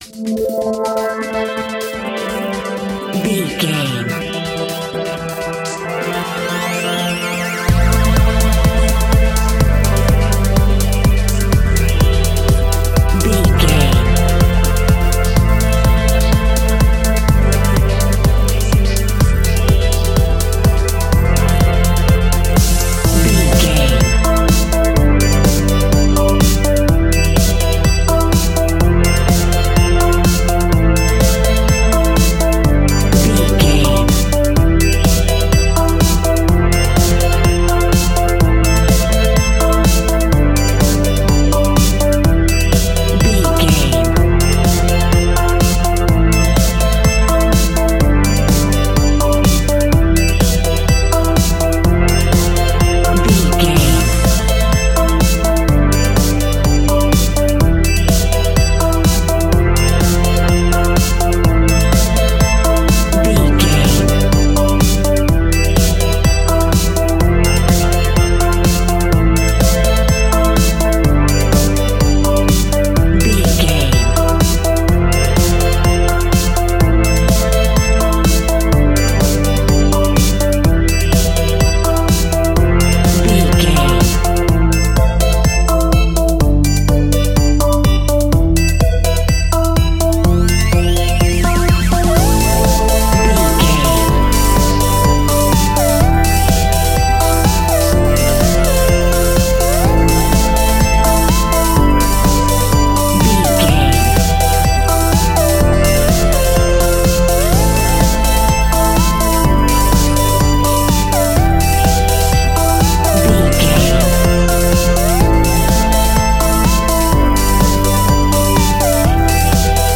Aeolian/Minor
groovy
uplifting
futuristic
driving
energetic
drum machine
synthesiser
electronica
synth leads
synth bass
synth pad
robotic